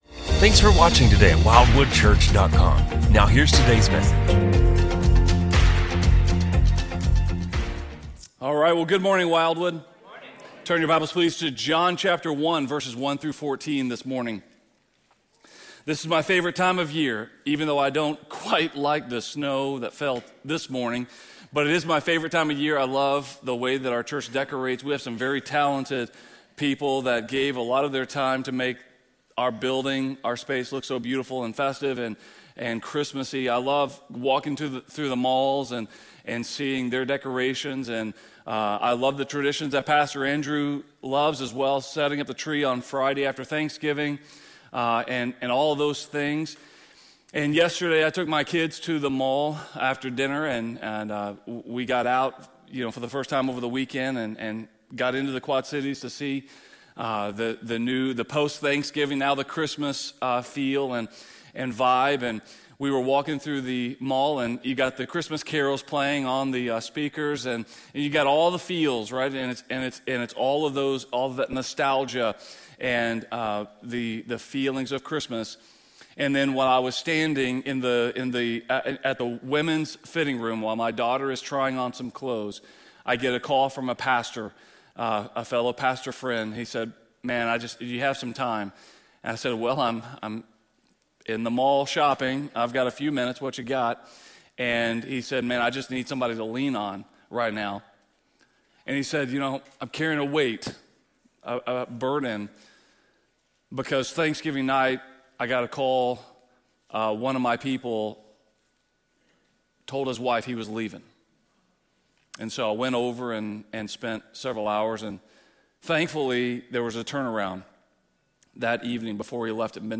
A message from the series "Promise of Christmas."